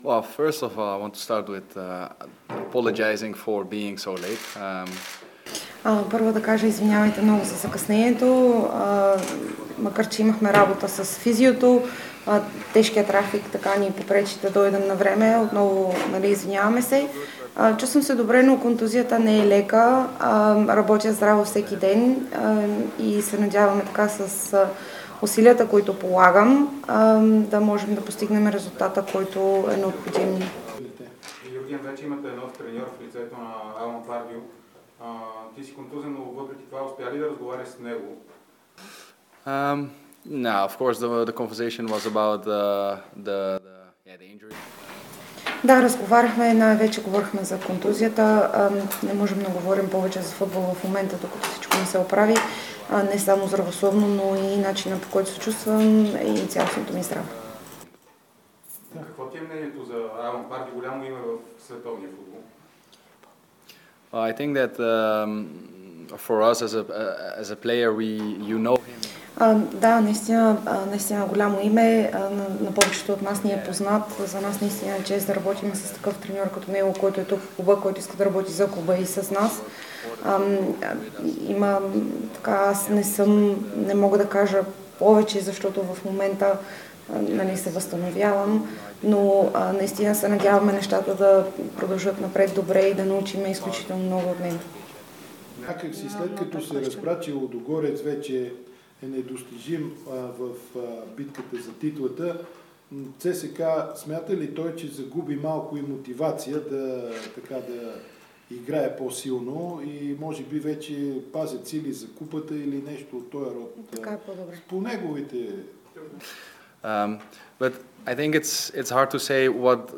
Нидерландецът получи наградата си и даде кратка пресконференция...